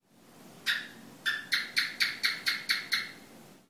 • Chirps with a short “chik-chik” or soft clicking sound
It sounds like a sharp “chuck-chuck-chuck” — almost like a little bird.
house gekko bali
sound-house-gekko-bali.mp3